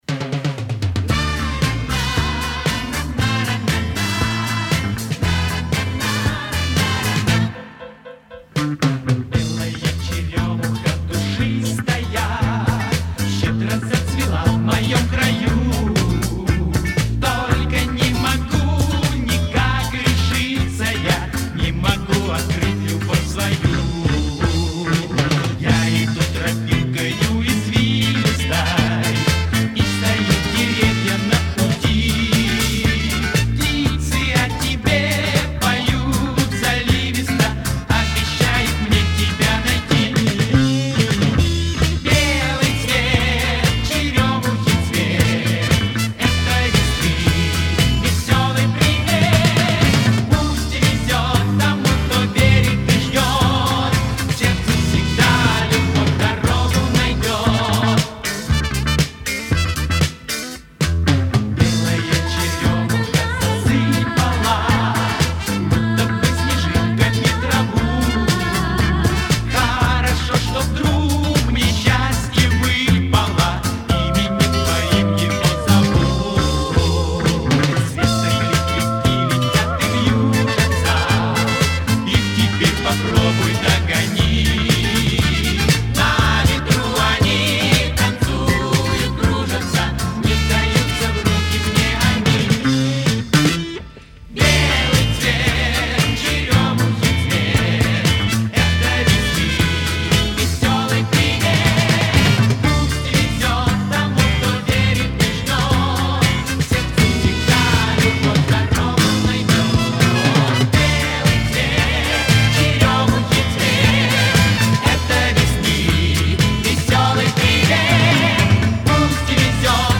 Встали к микрофонам, три-четыре раза пропели и …. свободны.